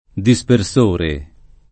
dispersore [ di S per S1 re ] s. m. (tecn.)